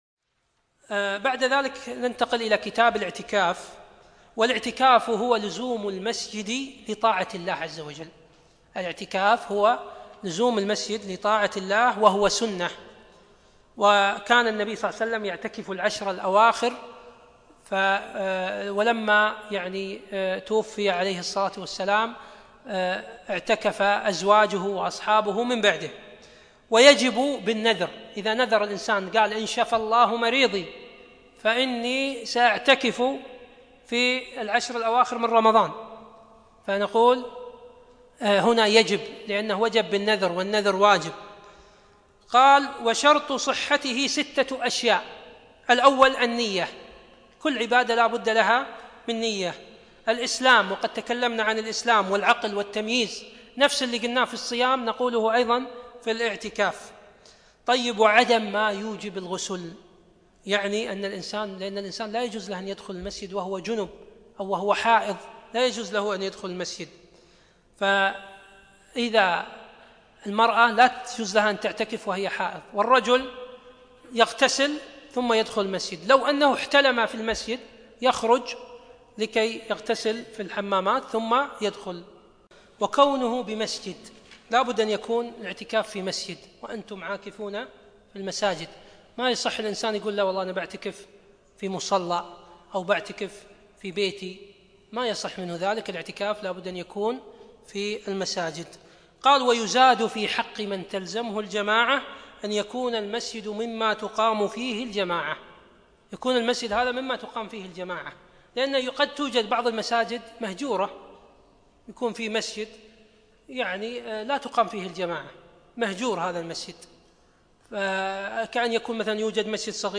يوم الاربعاء 25 شعبان 1437هـ الموافق 1 6 2016م في مسجد عايض المطيري الفردوس